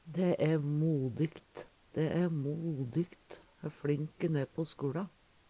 dæ æ modikt - Numedalsmål (en-US)